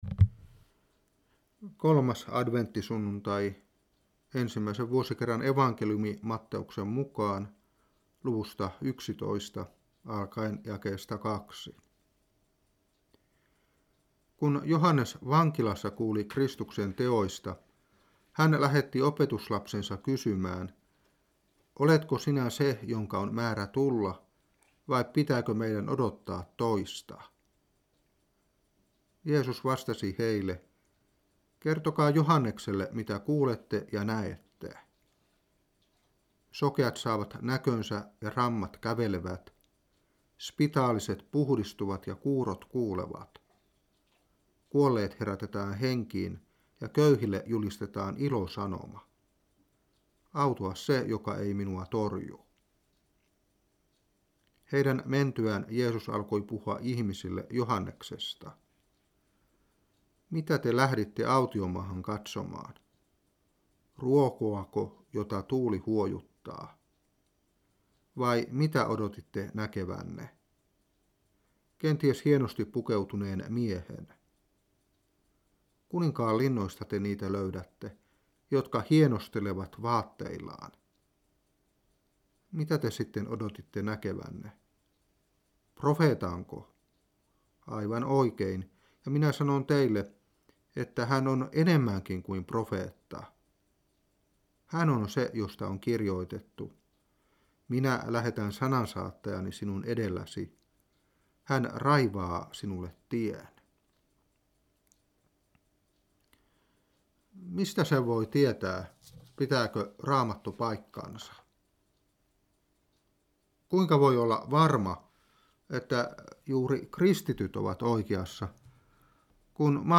Saarna 2003-12. Matt.11:2-10.